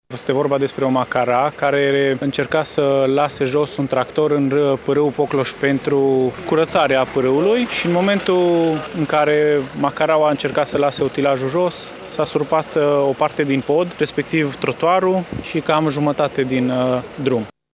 pompier.mp3